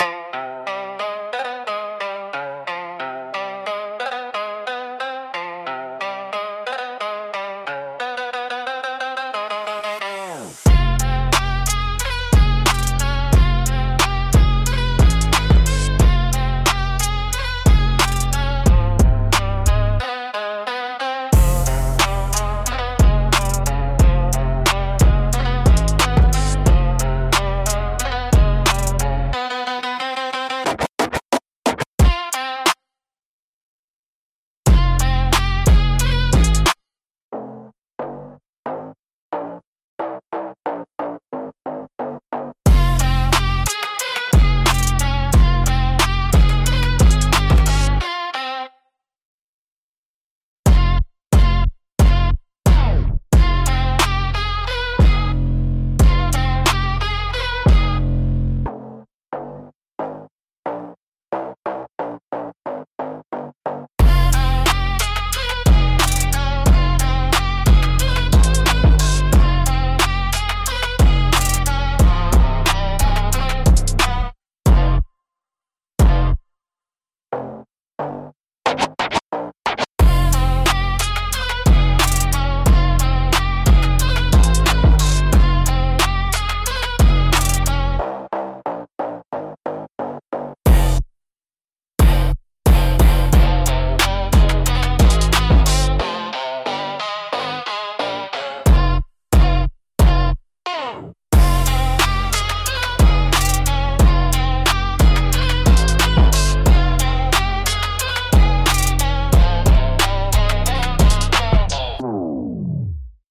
Instrumental - Ruthless & Reckless